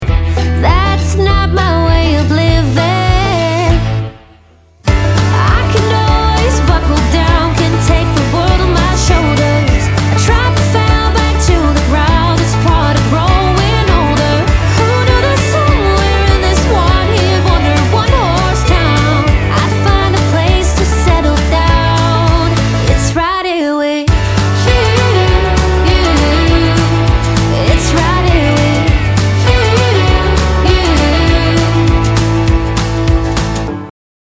indie folk